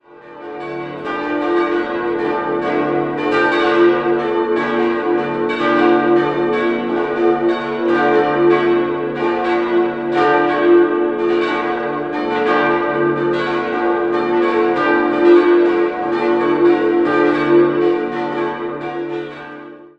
5-stimmiges erweitertes Salve-Regina-Geläute: d'-fis'-a'-h'-cis'' Die fis-Glocke wurde im Jahr 1908 von der Firma Oberascher in München gegossen.